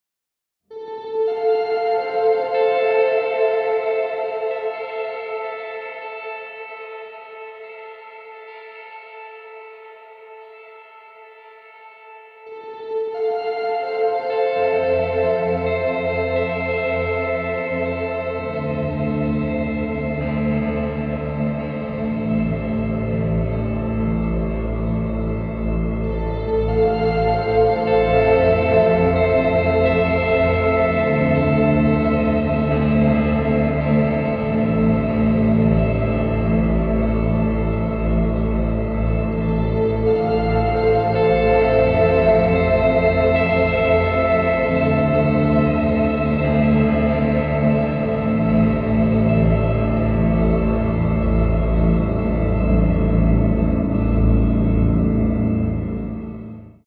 Experimental, ever-evolving guitar soundscapes
• Textures, soundscapes of shifting guitars
Audio demos